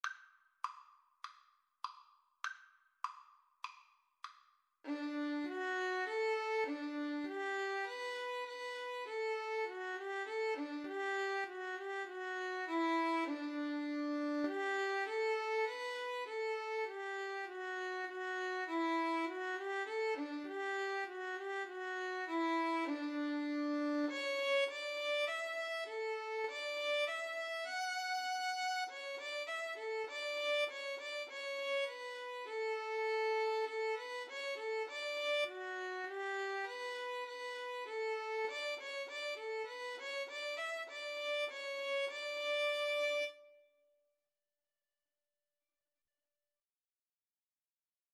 Free Sheet music for Violin Duet
D major (Sounding Pitch) (View more D major Music for Violin Duet )
4/4 (View more 4/4 Music)